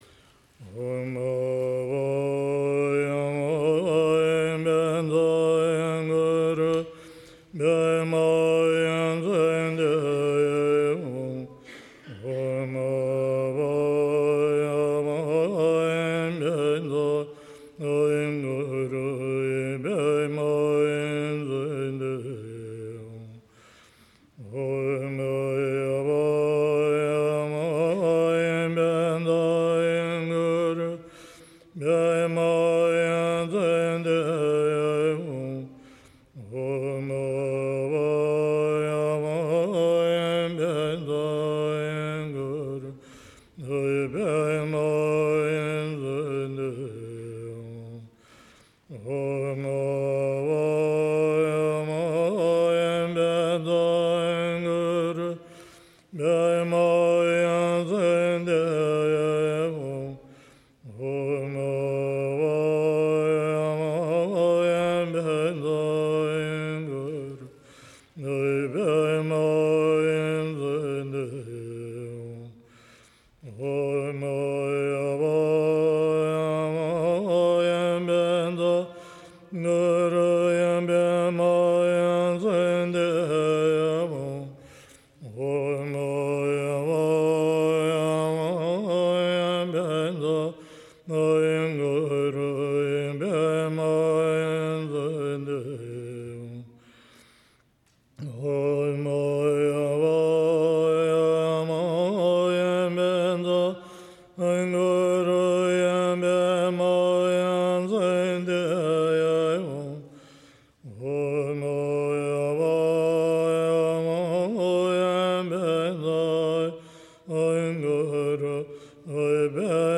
20-Vajra-Guru-Mantra.mp3